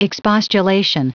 Prononciation du mot expostulation en anglais (fichier audio)
Prononciation du mot : expostulation